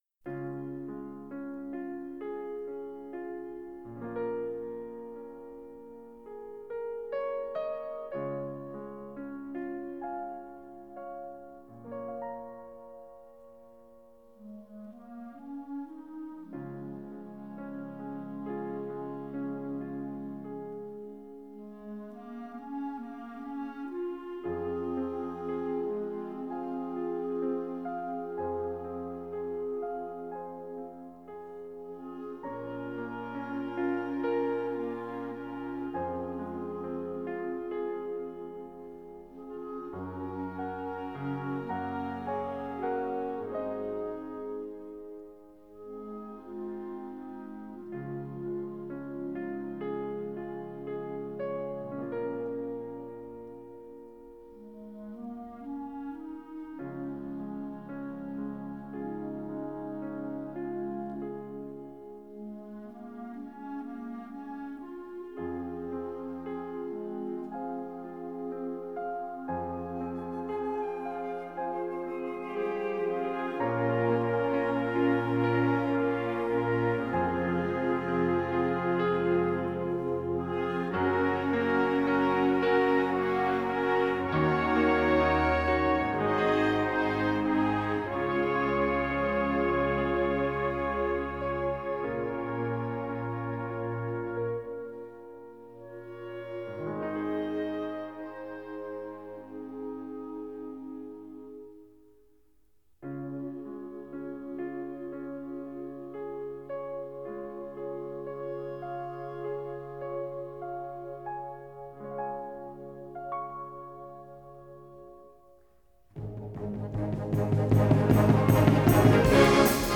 Partitions pour orchestre d'harmonie et - fanfare.